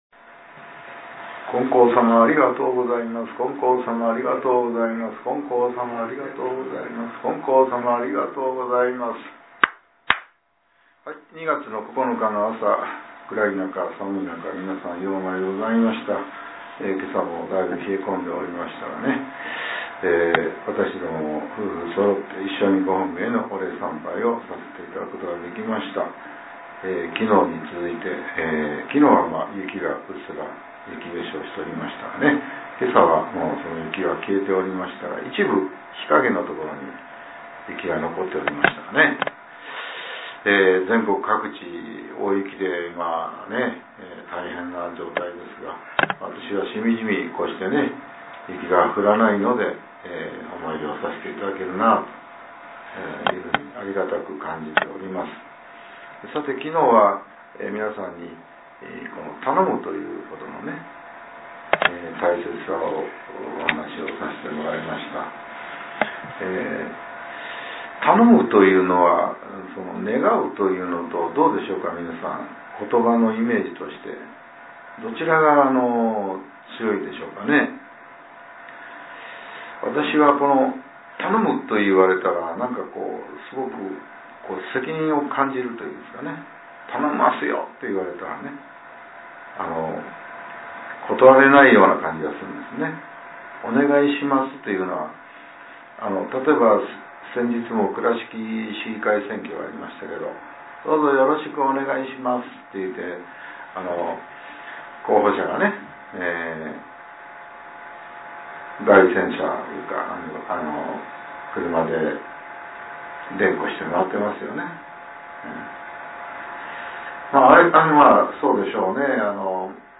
令和７年７月２日（朝）のお話が、音声ブログとして更新させれています。